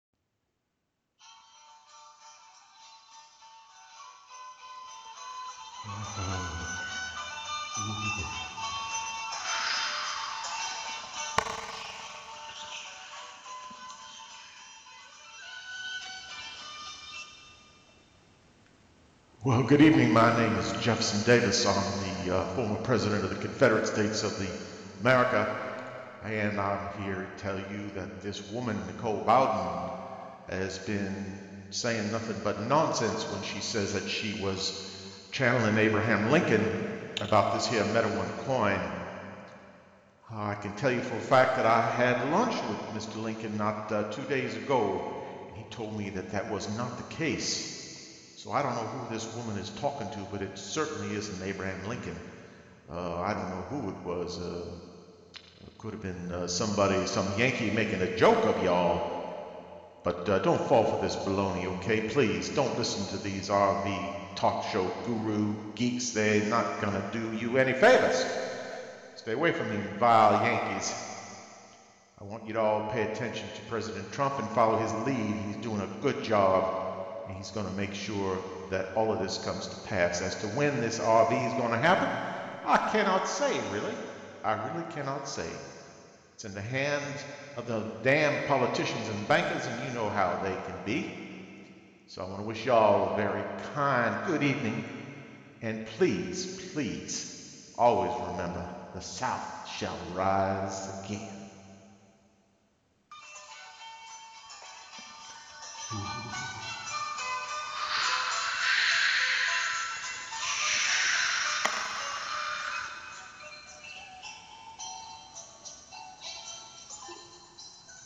The sound of him coming in the beginning and out at the end, really was clever to give it the full effect.